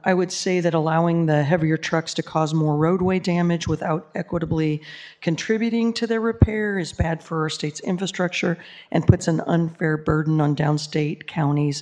State Sen. Meg Loughran Cappel, D-Shorewood, defended House Bill 2394 on the Senate floor Wednesday.